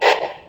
step.ogg.mp3